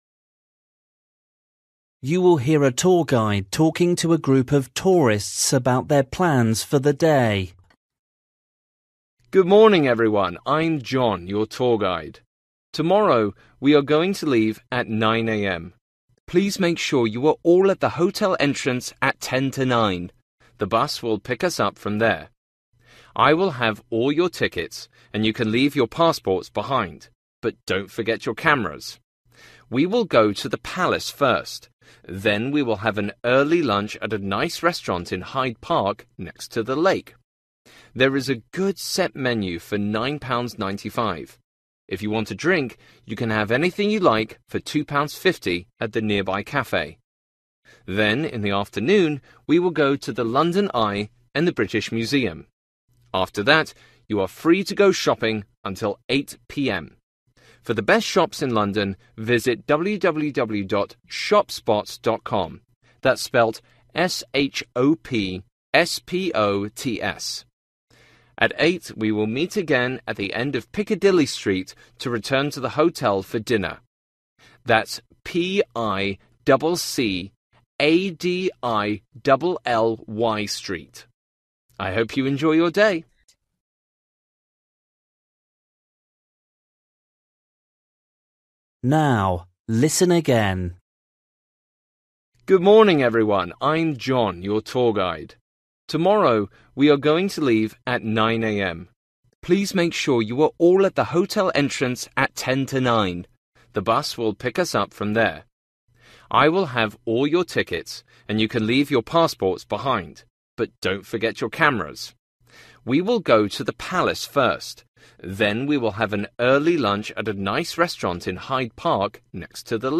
You will hear a tour guide talking to a group of tourists about their plans for the day.